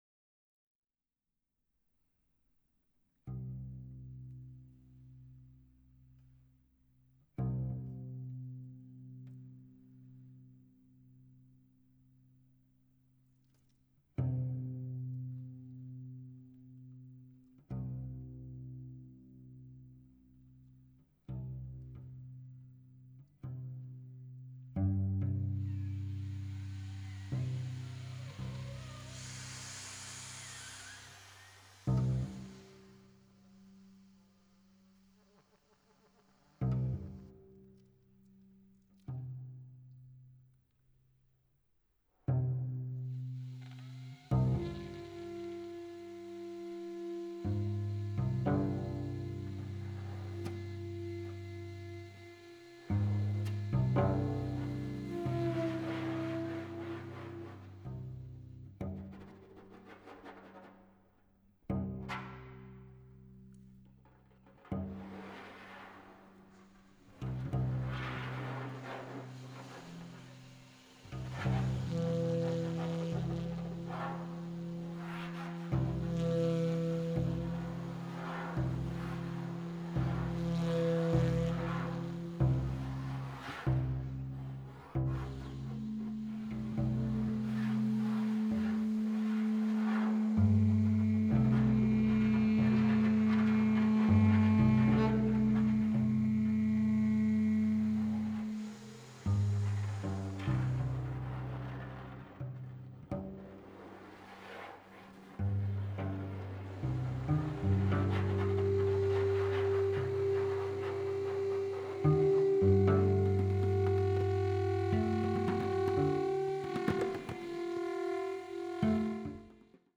cello
alto sax